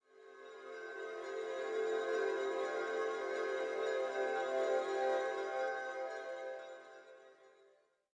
SFX魔法光效音效下载
SFX音效